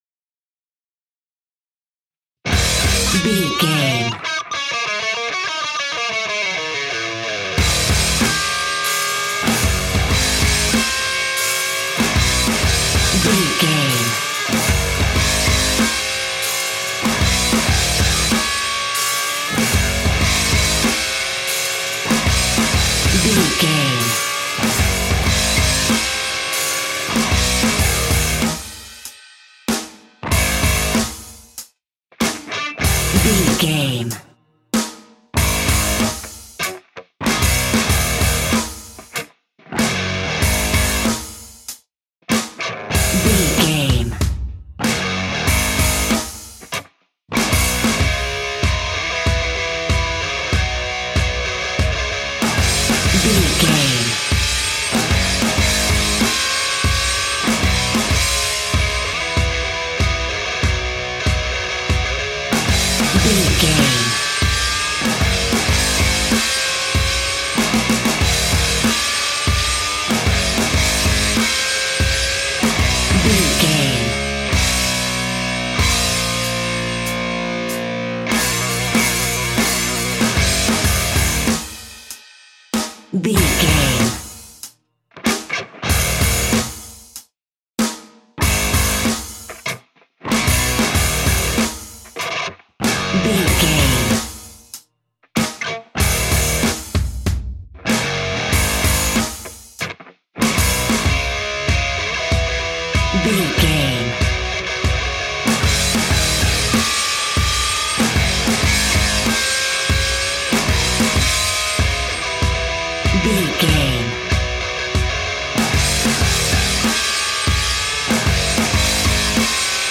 Epic / Action
Aeolian/Minor
E♭
hard rock
heavy metal
blues rock
distortion
rock guitars
Rock Bass
Rock Drums
heavy drums
distorted guitars
hammond organ